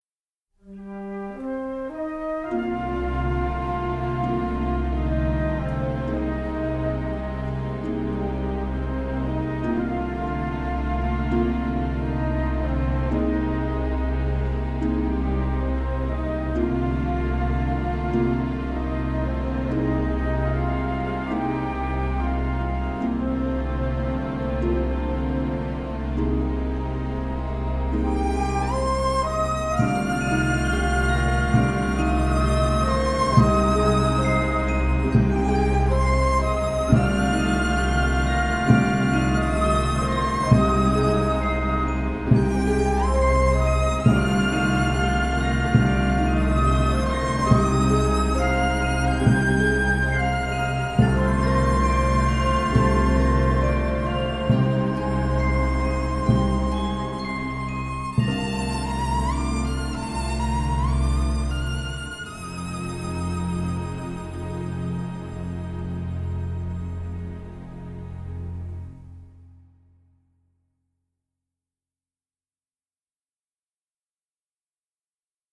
Aux rythmes tropicaux et accents aventureux bon teint
mélodie, cordes
Du western Silver Age sec et précis.
un western nerveux